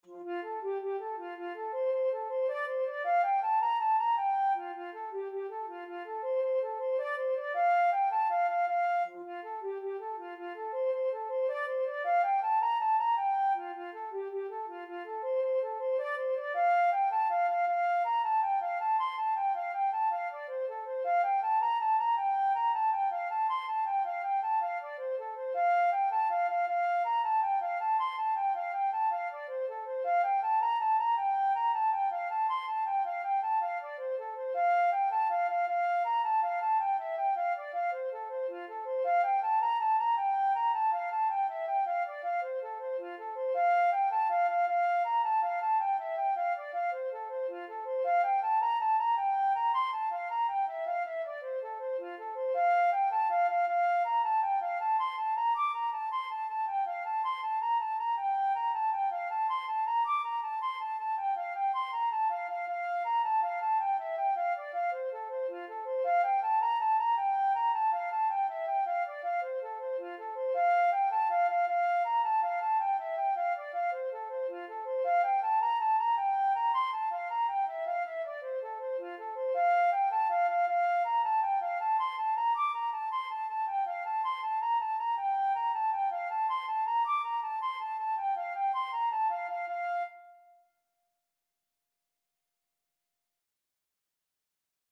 F major (Sounding Pitch) (View more F major Music for Flute )
6/8 (View more 6/8 Music)
F5-D7
Flute  (View more Intermediate Flute Music)
Traditional (View more Traditional Flute Music)